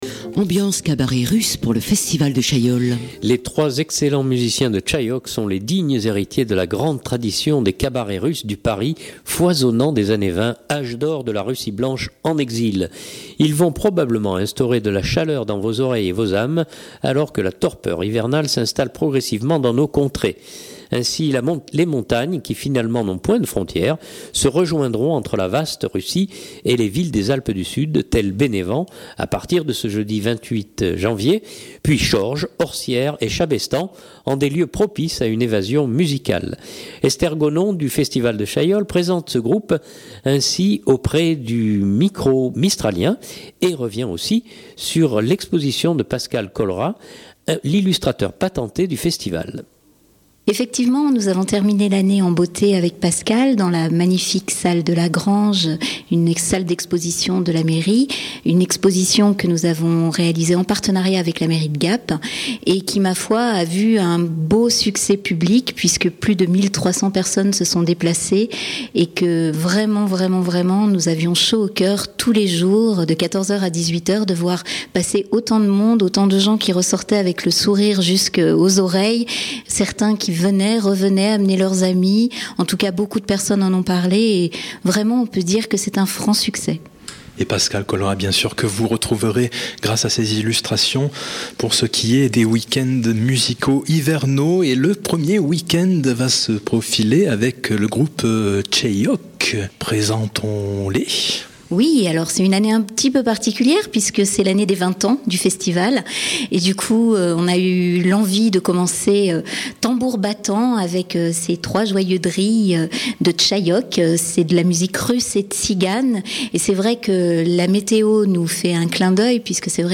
du festival de Chaillol présente ce groupe ainsi auprès du micro mistralien